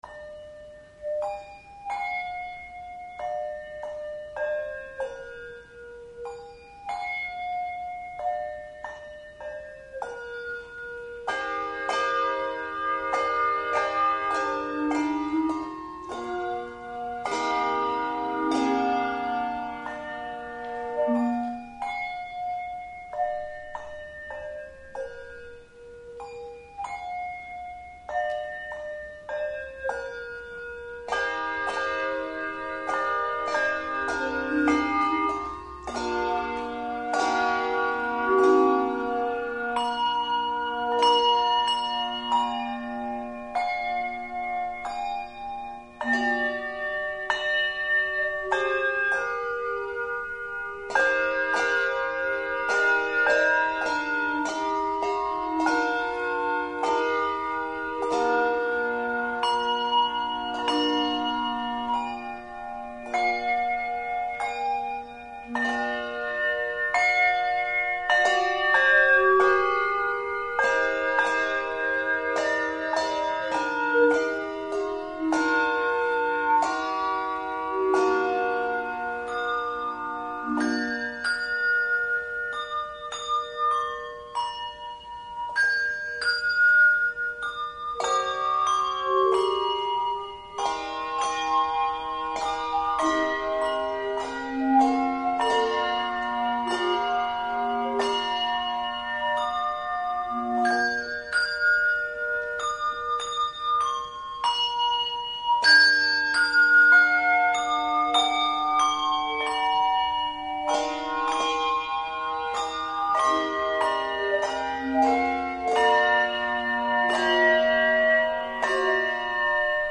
Handbell Quartet
Genre Sacred
No. Octaves 3 Octaves